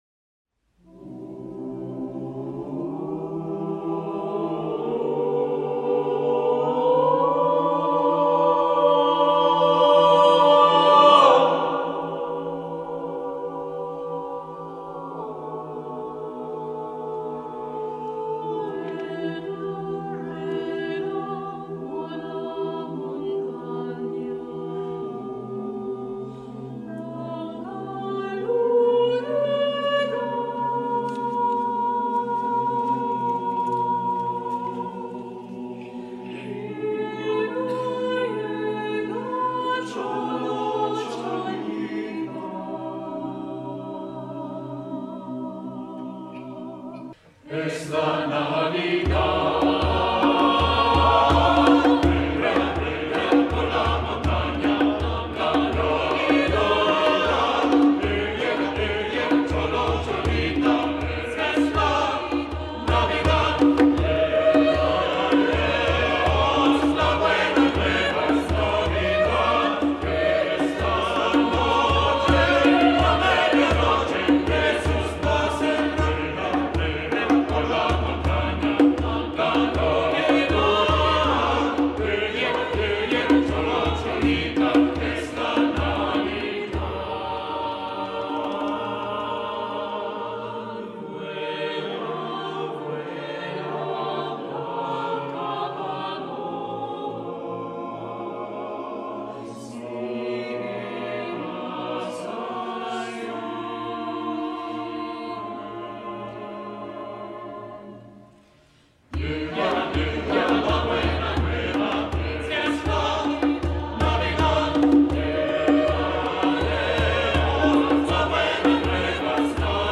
SATB a cappella + optional Percussion 3’30”